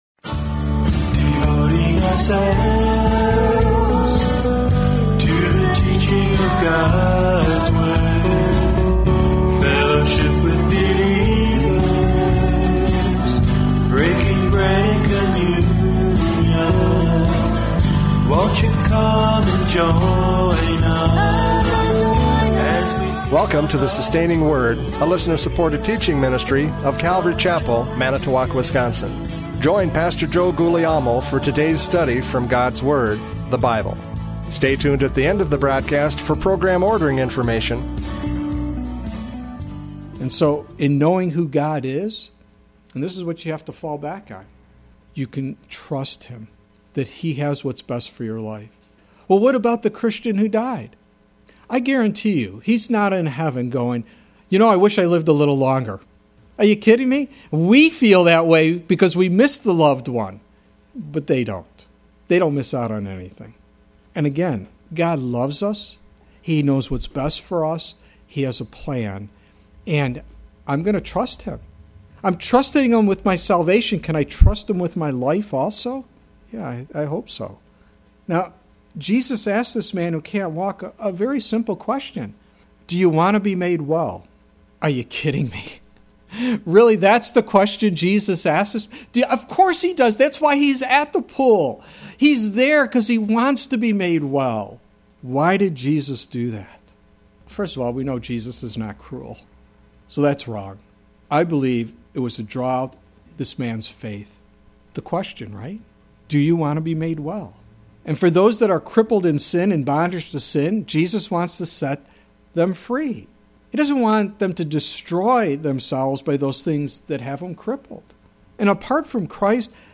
John 5:1-9 Service Type: Radio Programs « John 5:1-9 Do You Want to be Made Well?